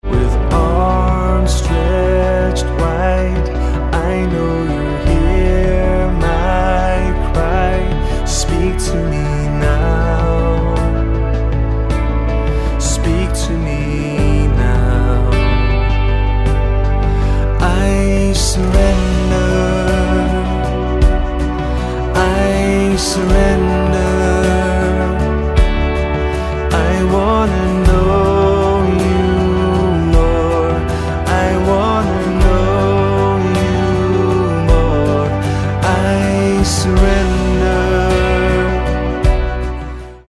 Bm